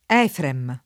vai all'elenco alfabetico delle voci ingrandisci il carattere 100% rimpicciolisci il carattere stampa invia tramite posta elettronica codividi su Facebook Efrem [ $ frem ] o Efraim [ efra & m o $ fraim ] (meno com. Efraimo [ efra & mo ]) pers. m.